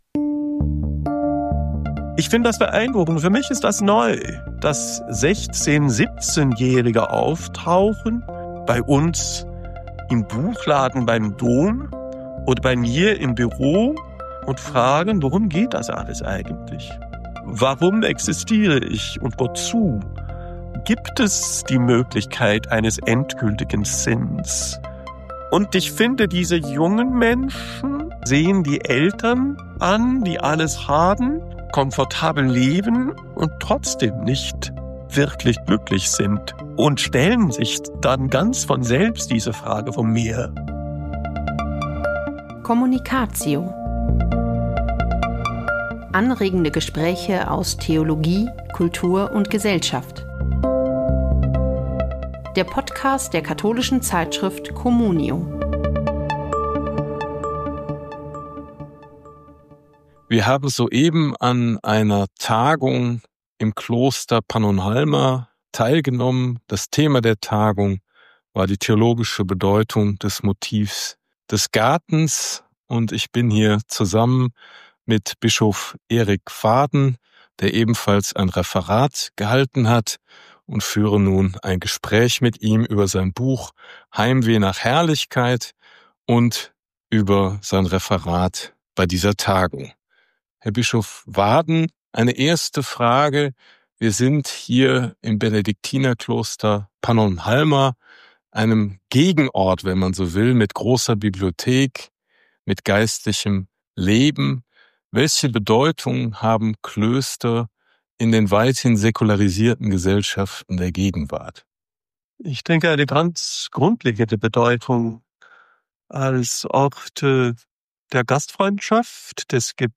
Beschreibung vor 4 Monaten Ende September 2025 fand in der ungarischen Erzabtei Pannonhalma eine internationale ökumenische Tagung statt.